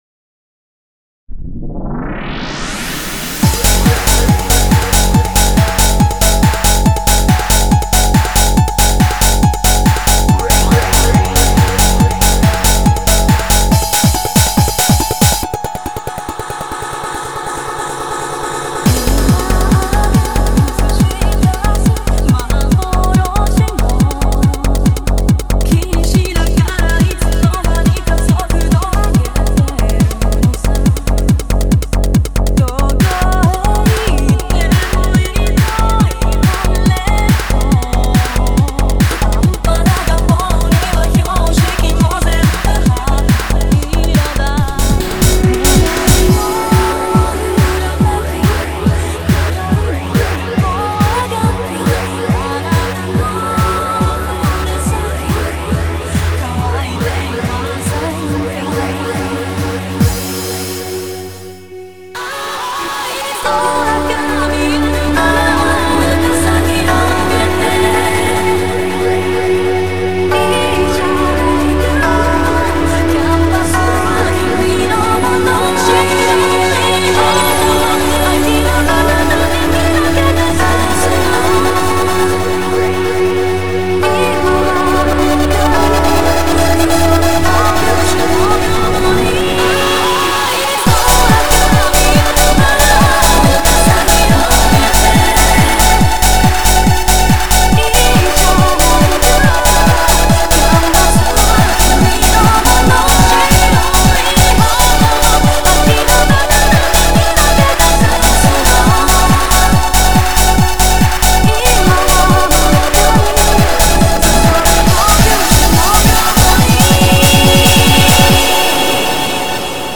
BPM70-140
I like this trance-y take on the song and I hope you do too!